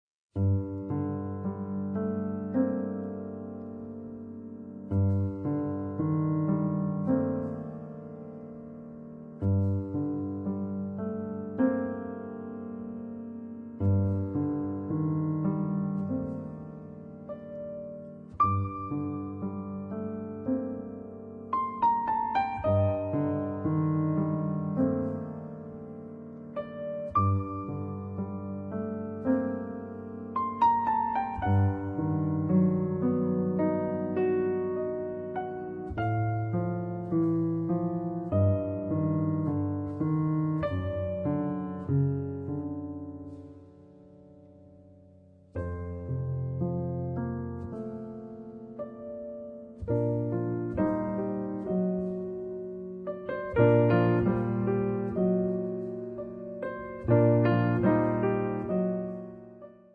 pianoforte
tromba
chitarra
contrabbasso
batteria